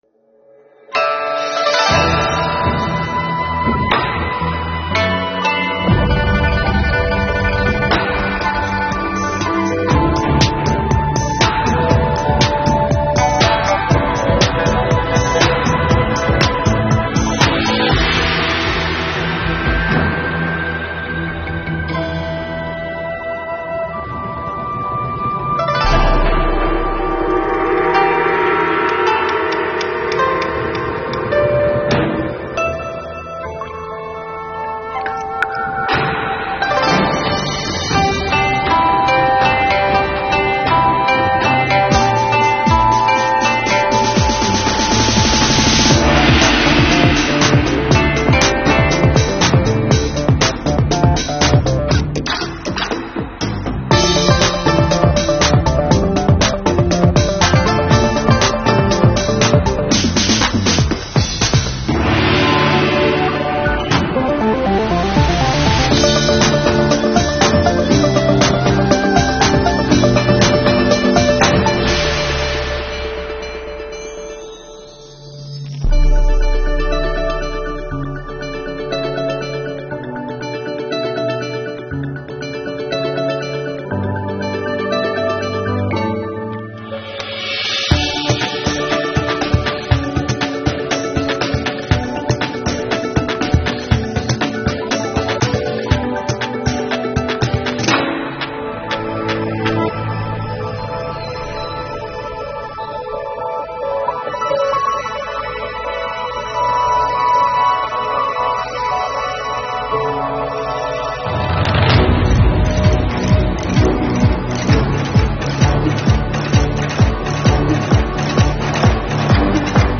不用同期声，没有解说词，